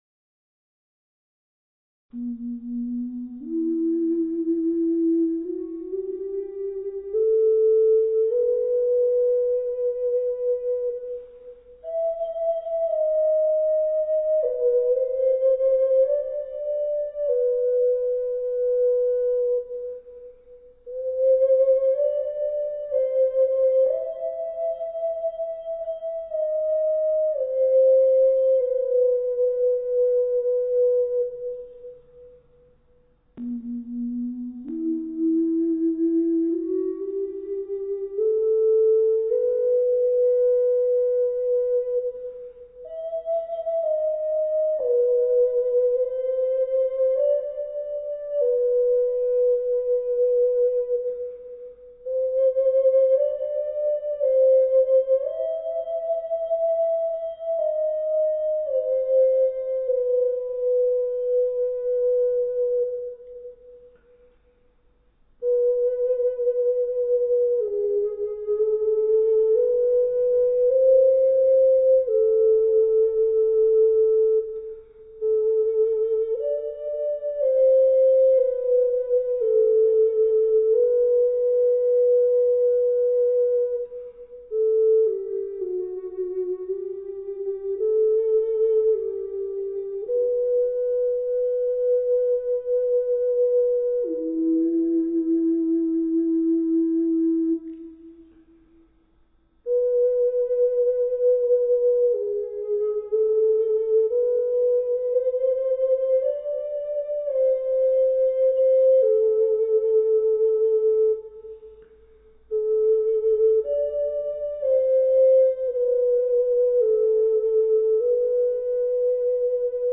Catalonia folk song : El cant dels ocells
Bass Ocarina
keyboard